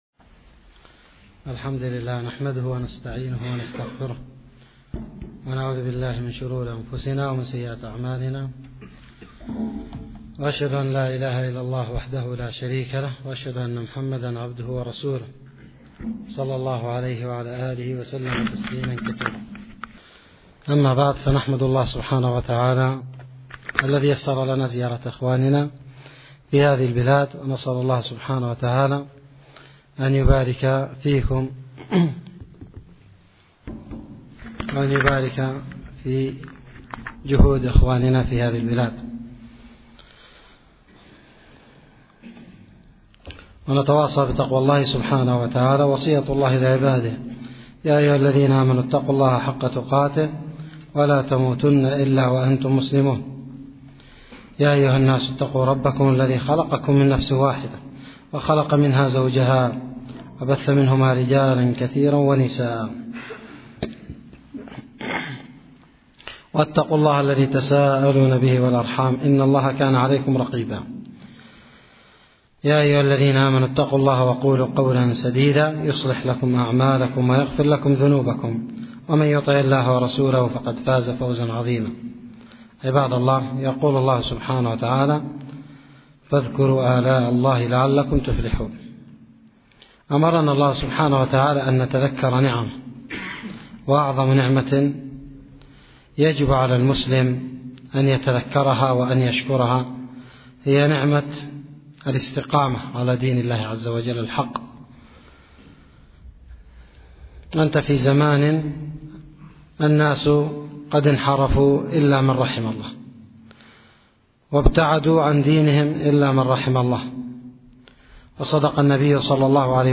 محاضره